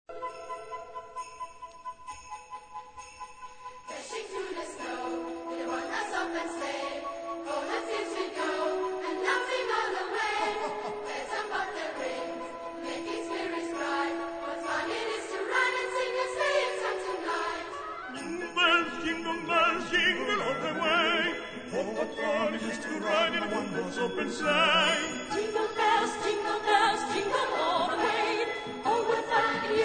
Piano & Vocal Score
key: E-major